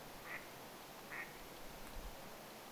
tuollainen sinisorsalintu, 1
tuollainen_sinisorsalintu.mp3